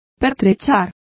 Also find out how pertrechado is pronounced correctly.